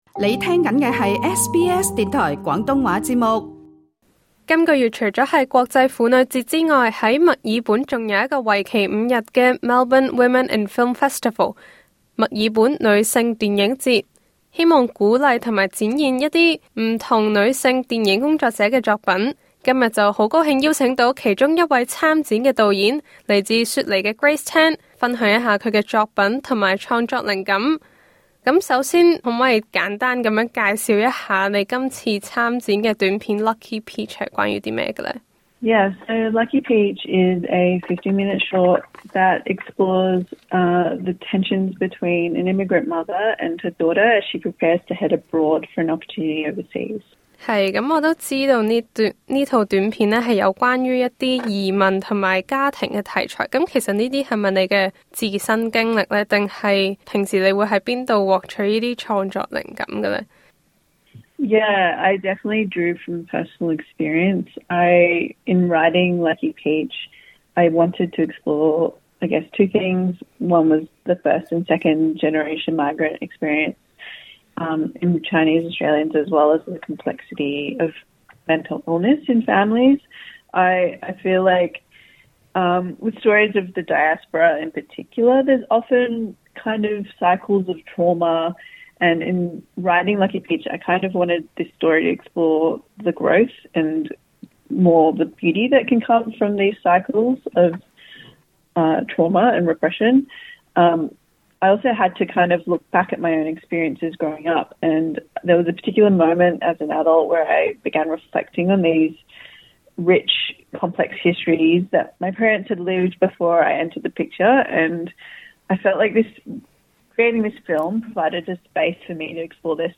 請留意足本錄音訪問。